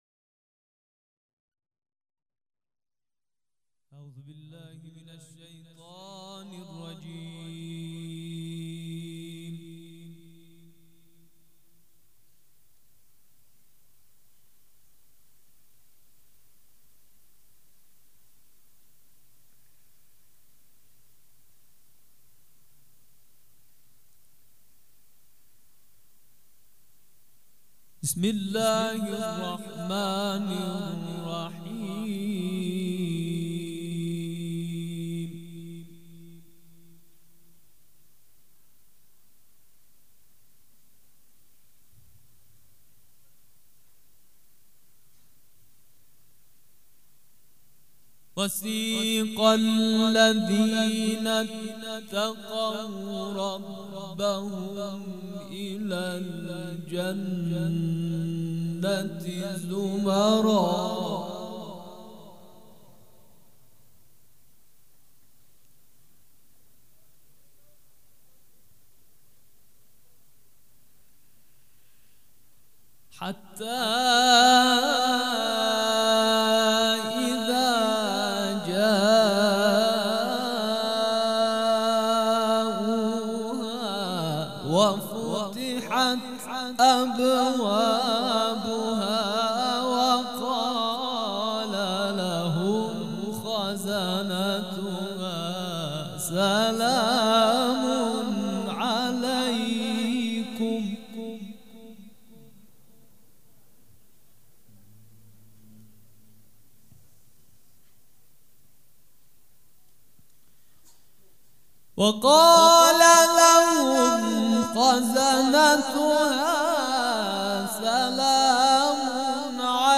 قرائت قرآن
قرائت قرآن کریم
مراسم عزاداری شب اول